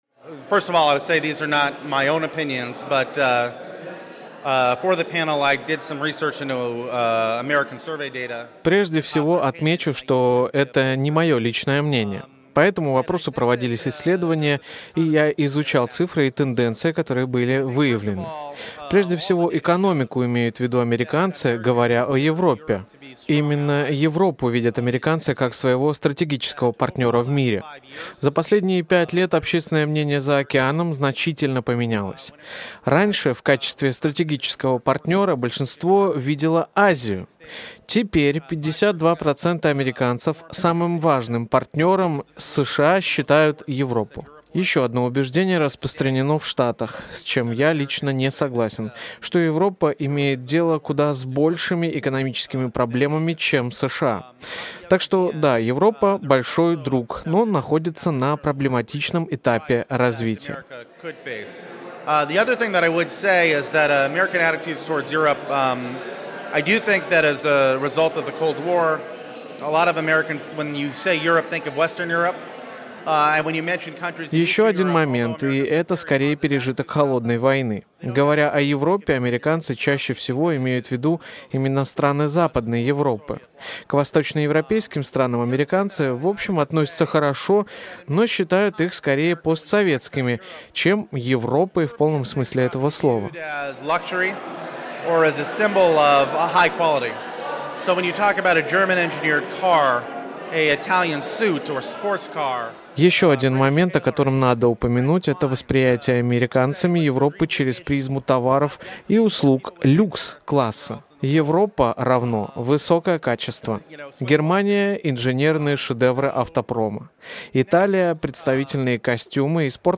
Представляем интервью с одним из лекторов D&A.